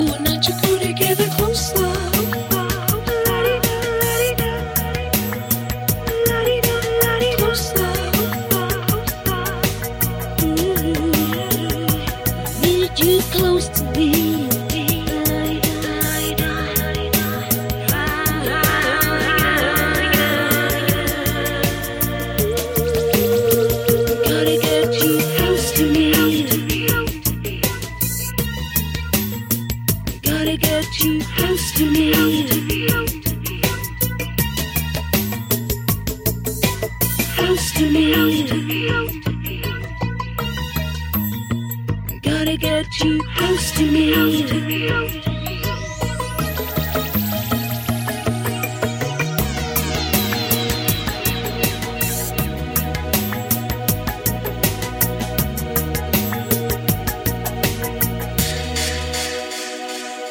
ethereal vocals
hypnotic synth riffs
Original Mix